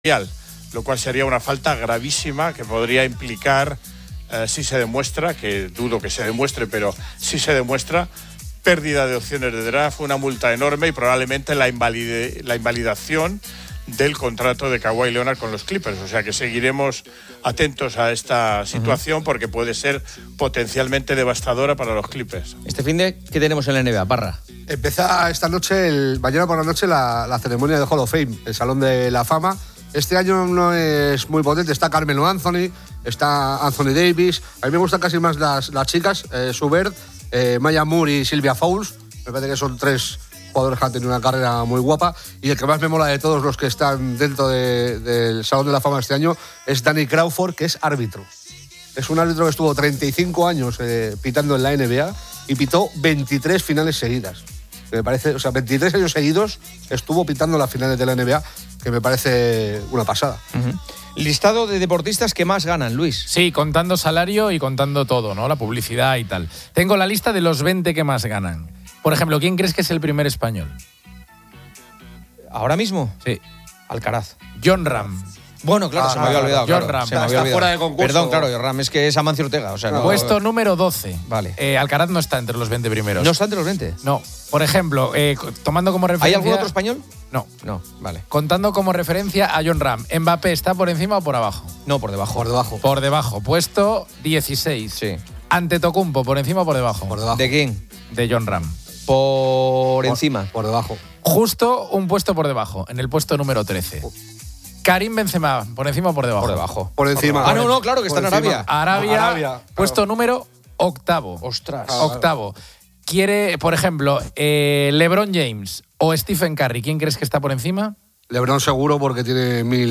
conversación